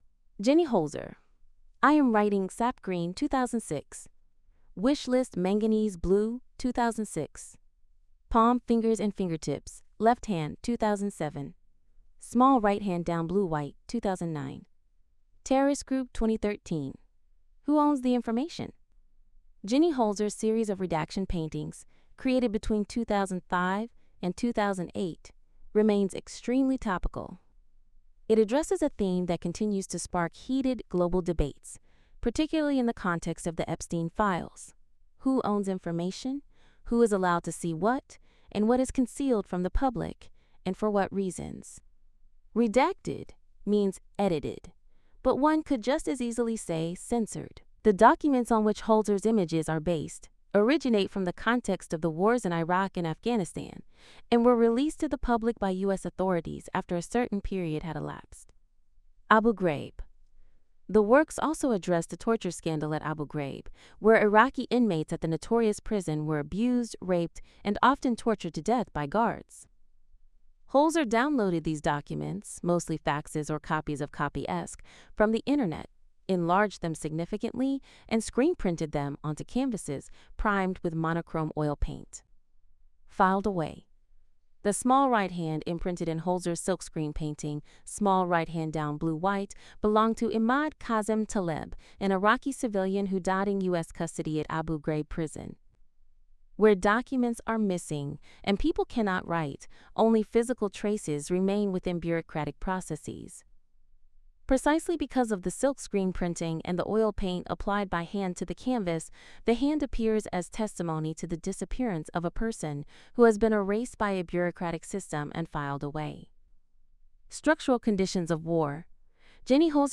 Hinweis: Die Audiotranskription ist von einer KI eingesprochen.